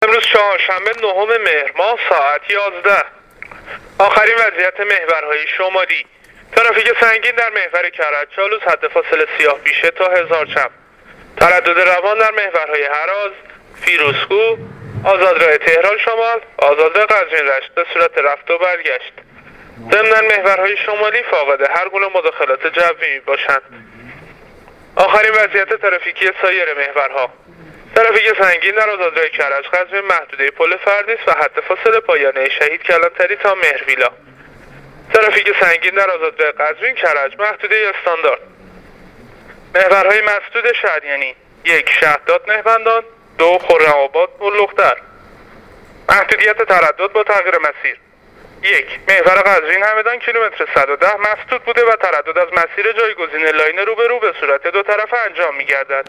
گزارش رادیواینترنتی از وضعیت ترافیکی جاده‌ها تا ساعت ۱۱ چهارشنبه ۹ مهر